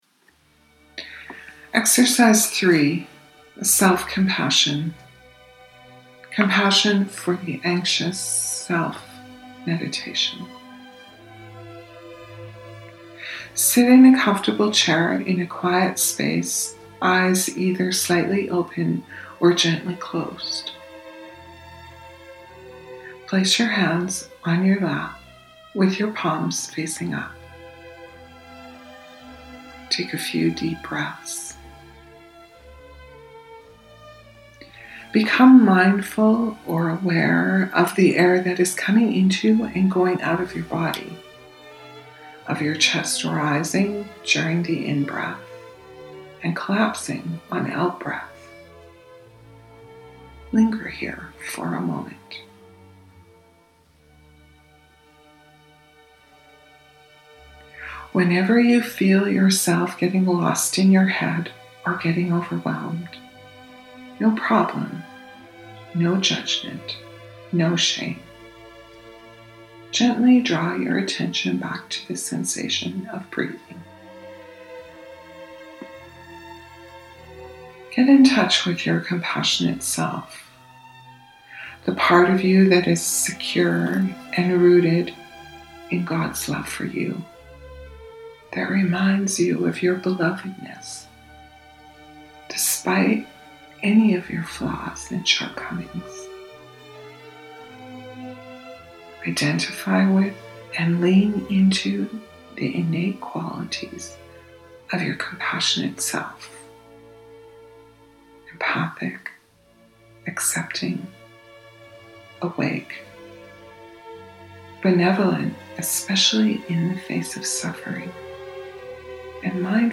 The meditation below is an attempt to CALM our ANXIOUS SELF. This active yet quiet form of prayer prepares us to approach that anxious part of the self from the deeper, kinder, loving, and empathic COMPASSIONATE SELF.
Meditation-for-the-Anxious-Self-.mp3